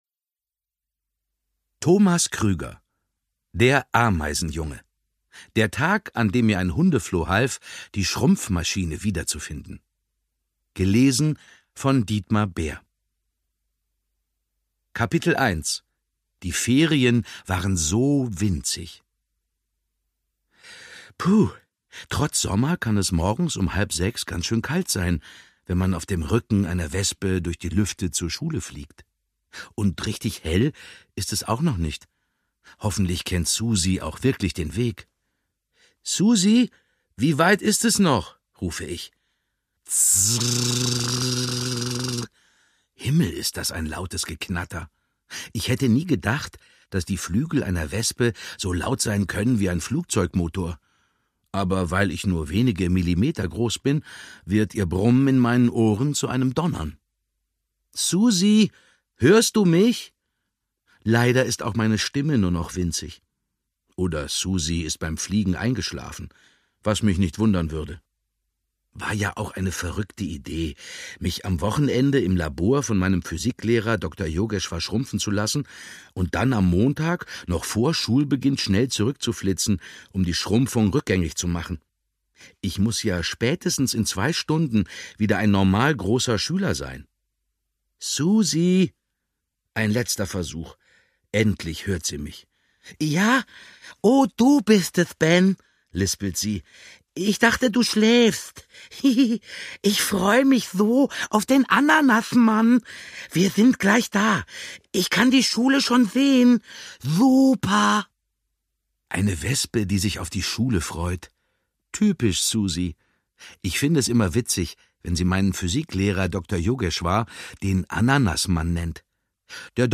Dietmar Bär (Sprecher)
2018 | Ungekürzte Lesung
Genial gelesen und gespielt von Dietmar Bär